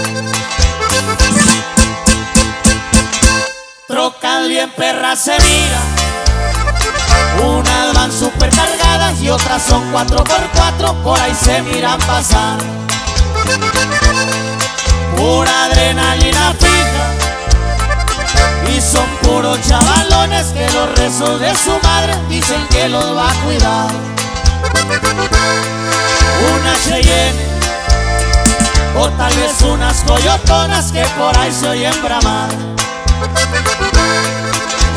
# Regional Mexican